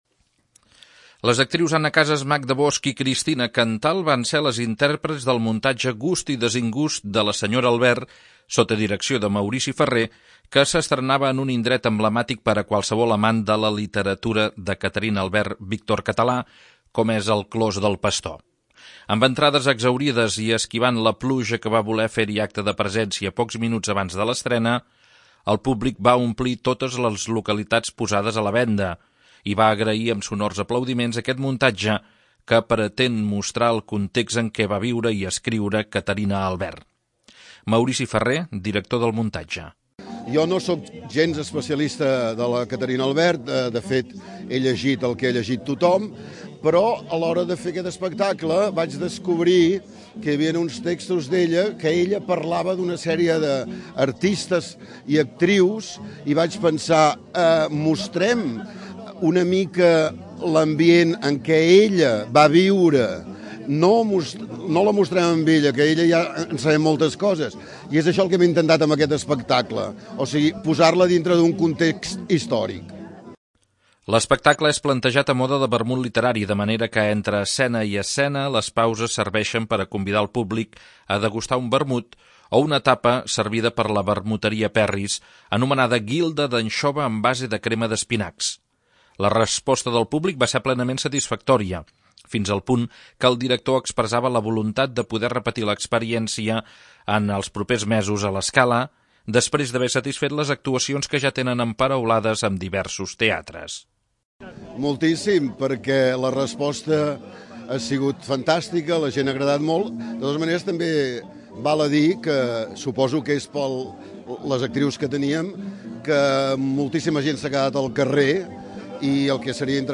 Tot l'espectacle respirava un aire irònic, a voltes a ritme de comèdia, com va ser l'escena muntada a partir del poema "La vaca cega", de Joan Maragall.